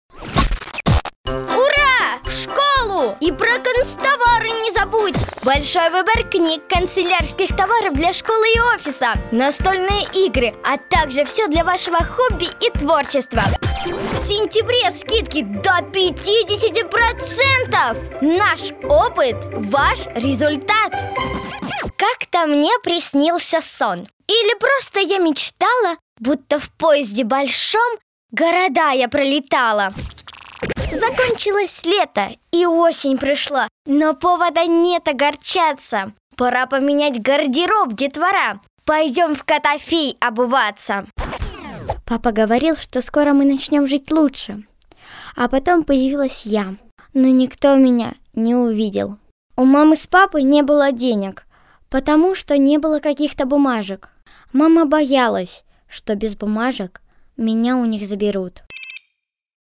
Весёлая, бодрая, информационная подача.
Тракт: Rode NT-2a, NI KOMPLETE AUDIO 6.